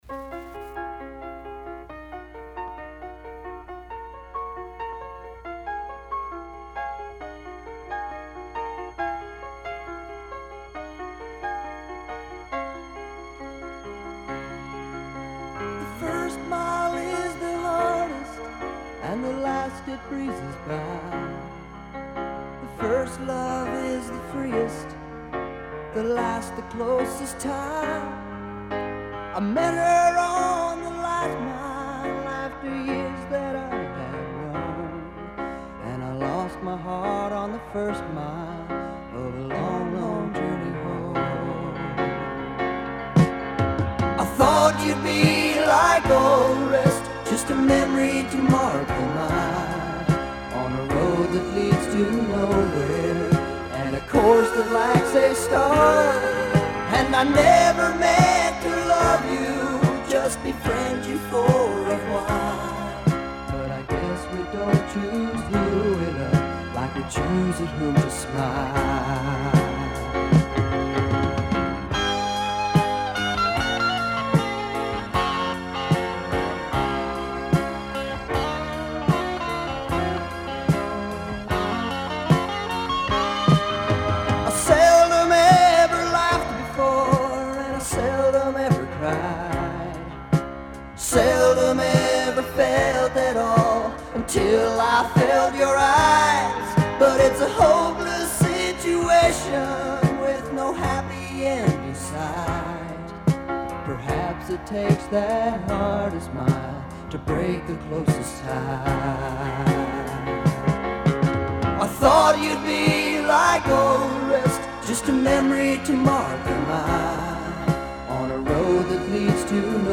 country-influenced single